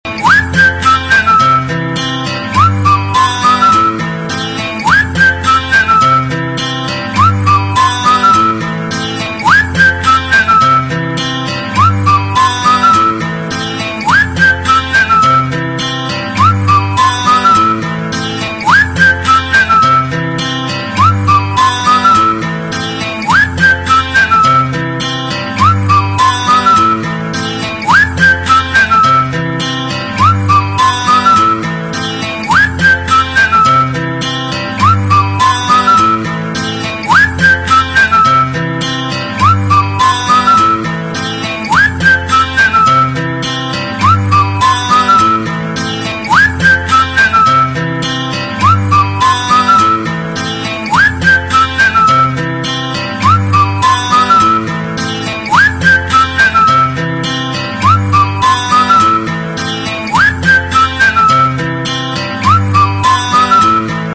纯音乐